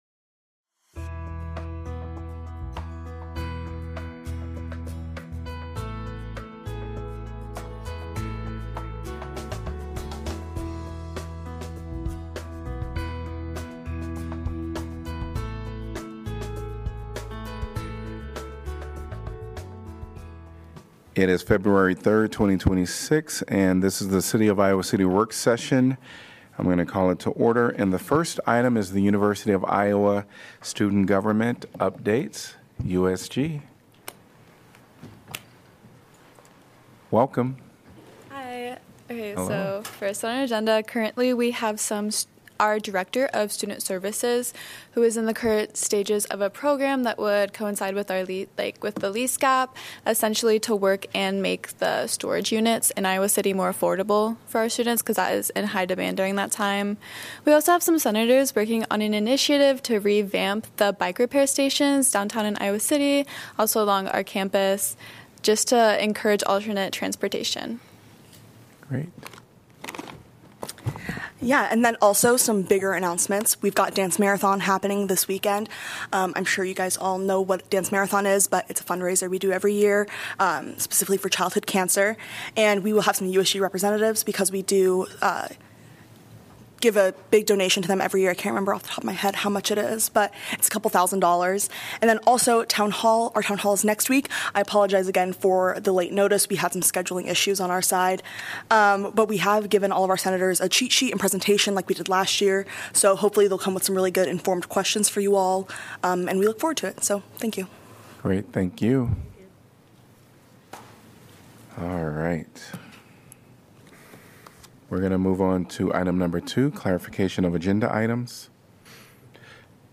Iowa City City Council Work Session of February 3, 2026